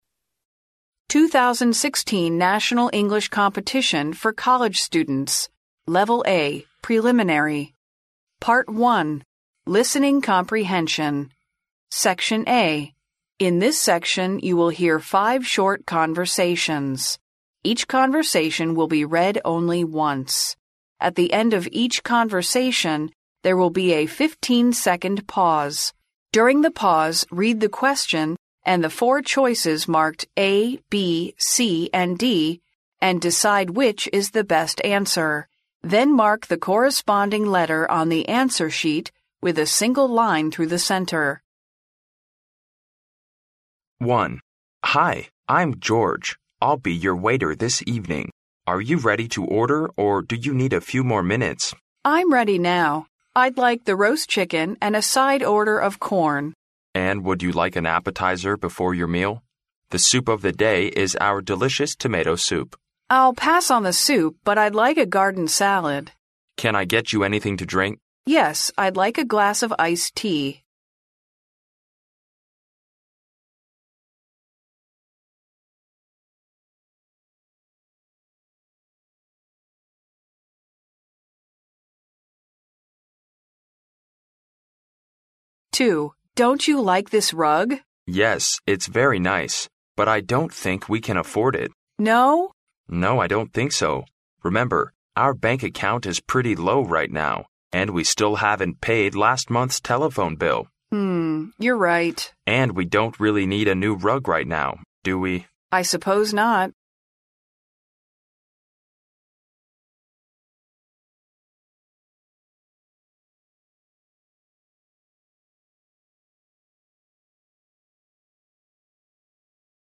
In this section, you will hear five short conversations. Each conversation will be read only once. At the end of each conversation, there will be a fifteen-second pause.